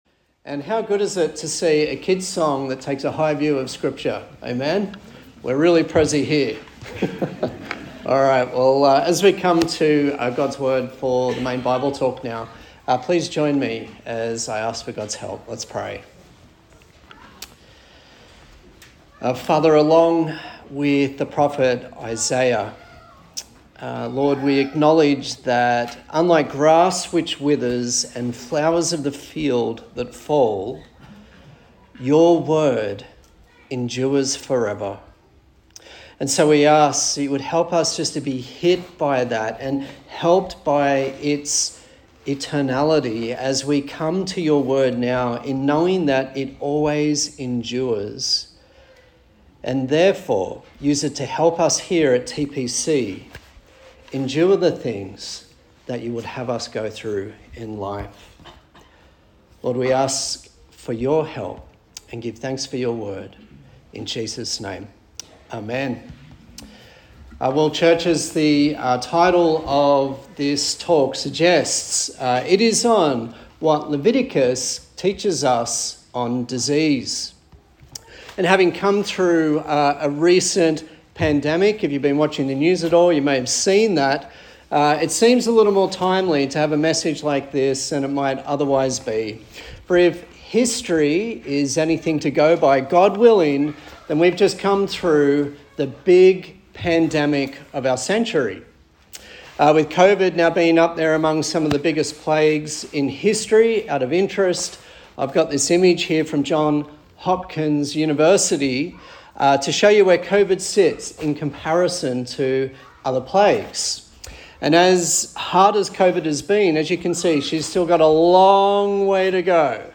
Leviticus Passage: Leviticus 13 and 14 Service Type: Morning Service